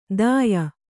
♪ dāya